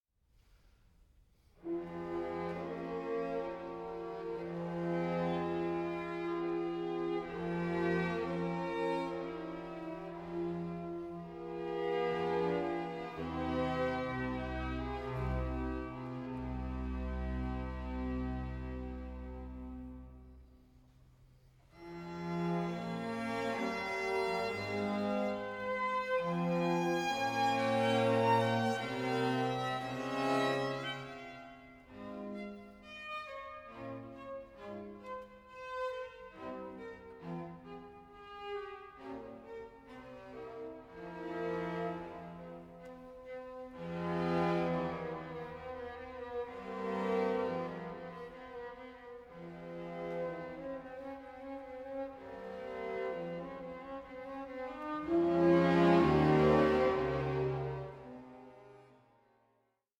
PASSIONATE VOCAL AND SYMPHONIC MUSIC FROM THE CLASSICAL ERA
period-instruments ensembles